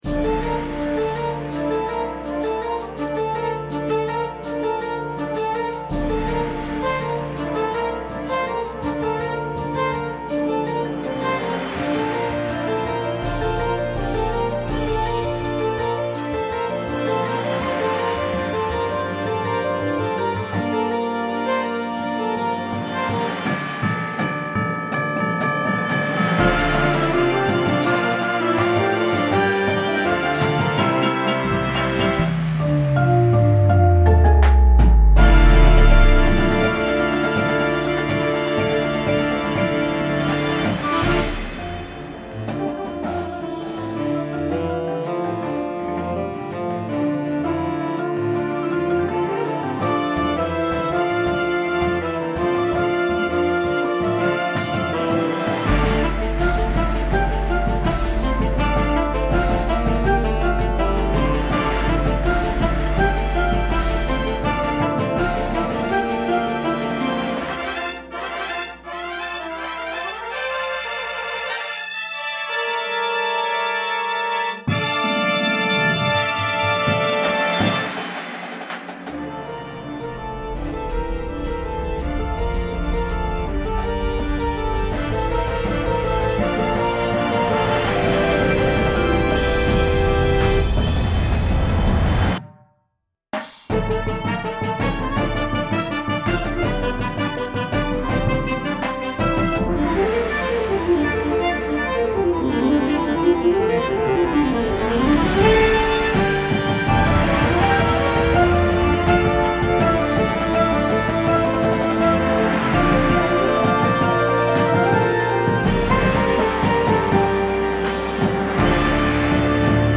• Flute
• Clarinet 1, 2
• Alto Sax 1, 2
• Trumpet 1
• Horn in F
• Trombone 1, 2
• Tuba
• Snare Drum
• Synthesizer – Two parts
• Marimba – Two parts
• Vibraphone – Two parts